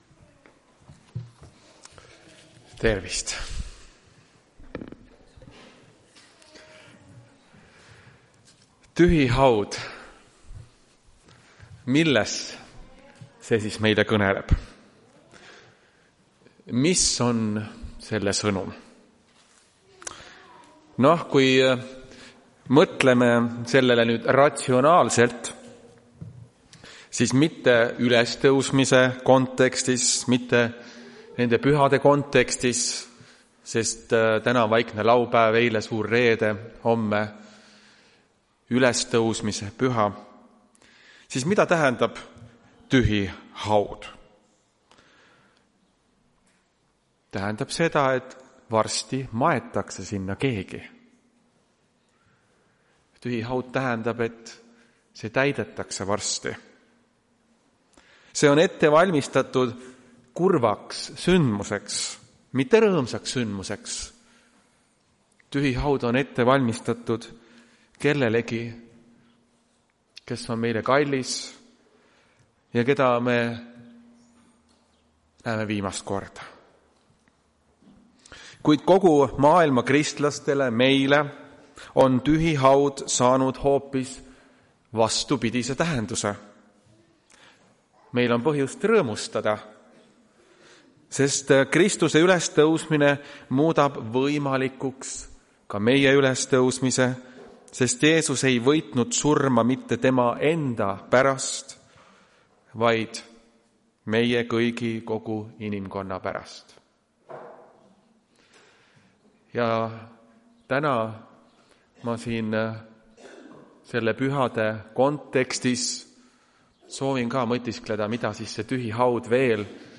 Tartu adventkoguduse 19.04.2025 hommikuse teenistuse jutluse helisalvestis.